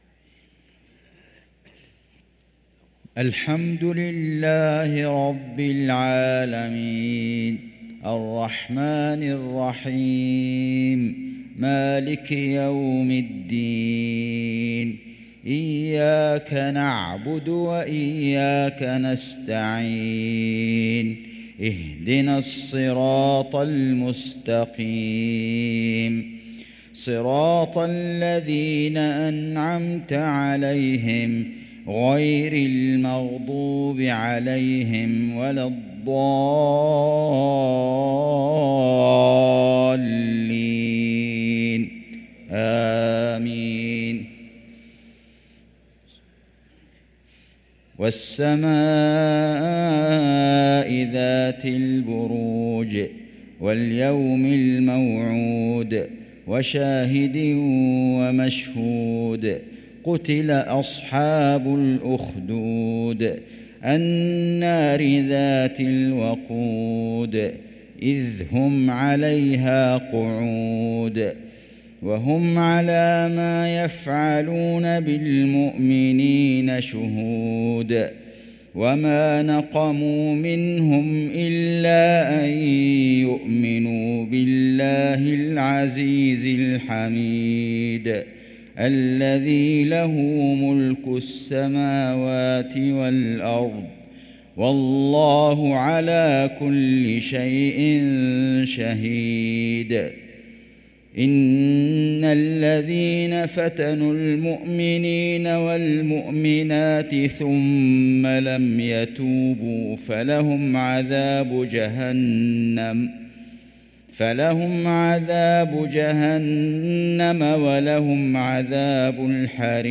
صلاة العشاء للقارئ فيصل غزاوي 6 جمادي الأول 1445 هـ
تِلَاوَات الْحَرَمَيْن .